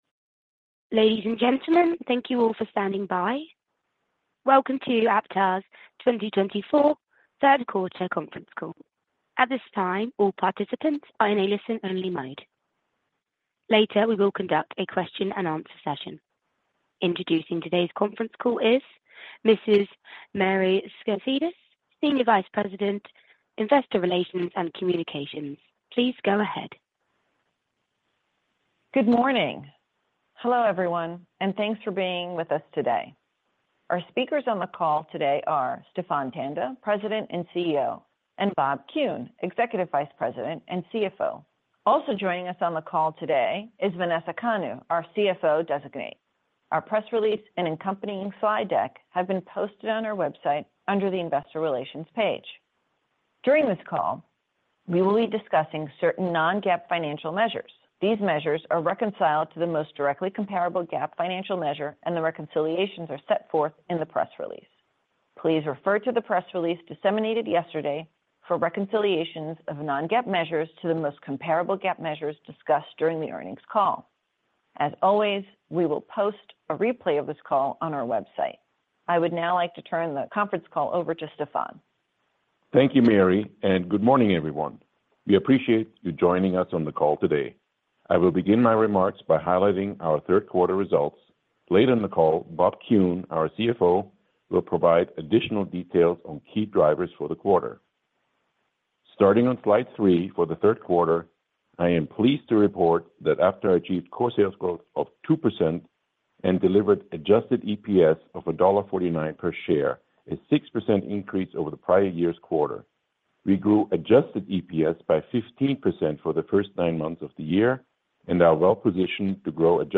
Q3 2024 Results Earnings Conference Call MP3 (opens in new window)